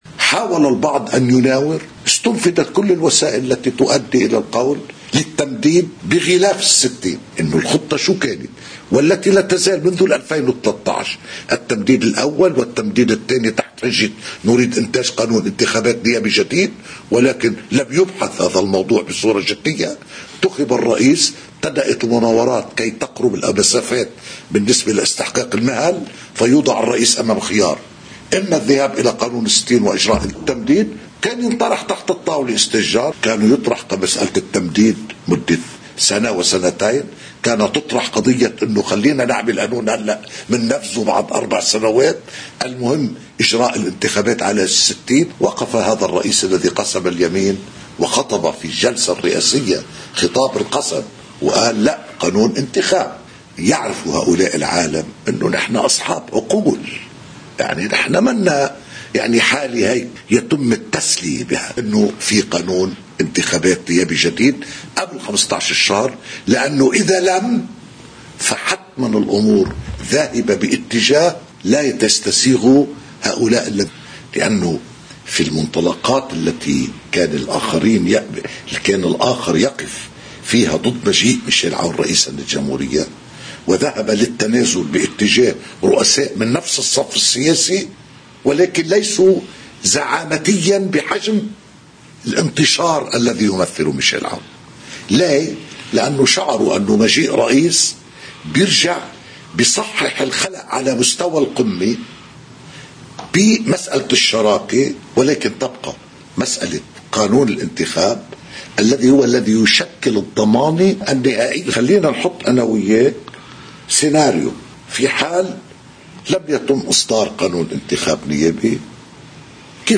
مقتطف من حديث نائب رئيس مجلس النواب السابق ايلي الفرزلي لقناة الـ”OTV” ضمن برنامج “بلا حصانة”: